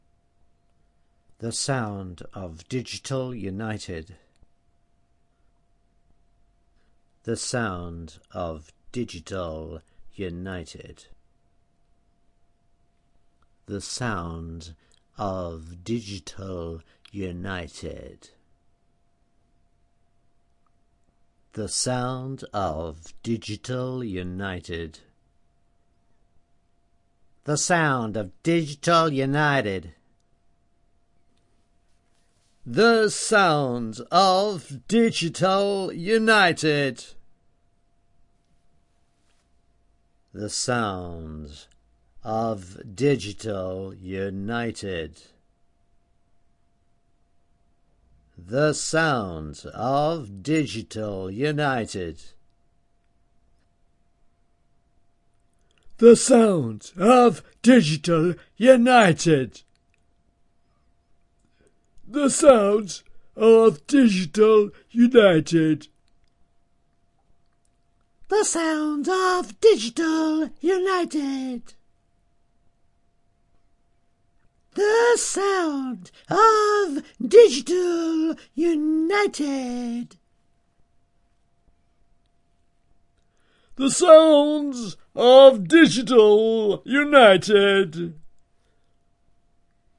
标签： voice fantasy vocal request male videogame
声道立体声